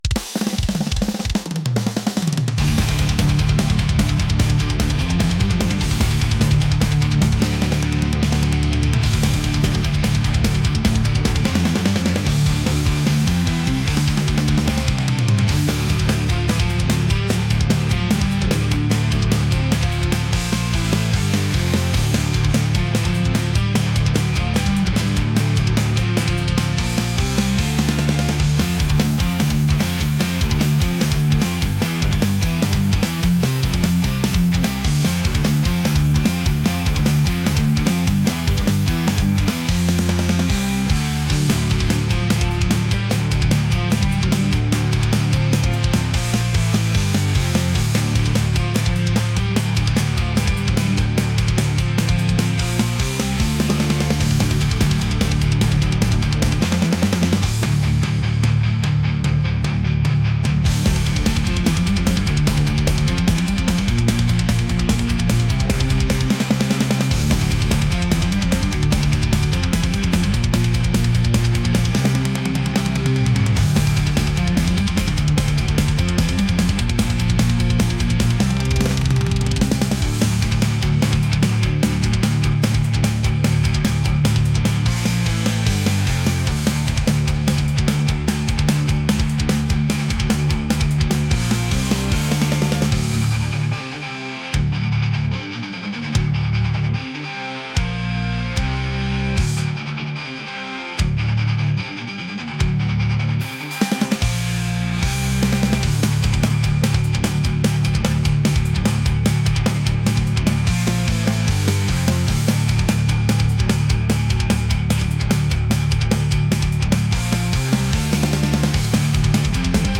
aggressive | metal